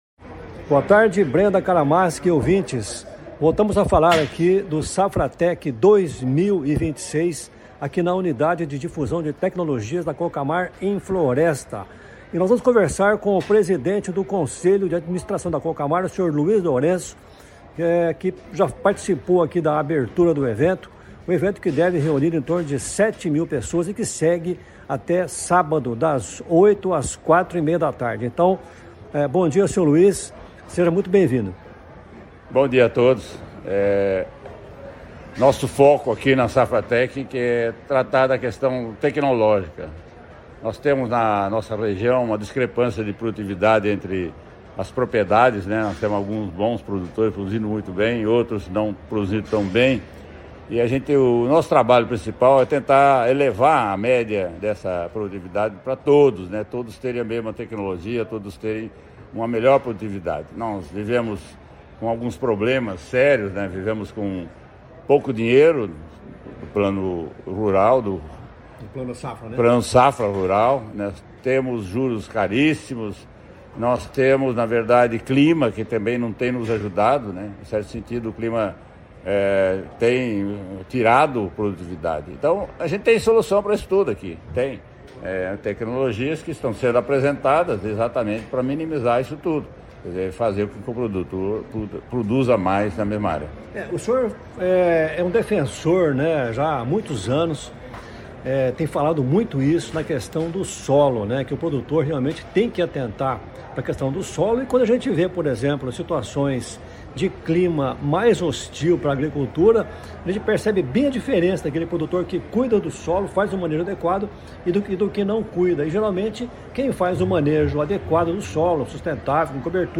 A entrevista foi realizada no estúdio móvel da CBN, instalado na Unidade de Difusão Tecnológica da Cocamar (UDT), em Floresta, onde ocorre a edição 2026 do Safratec.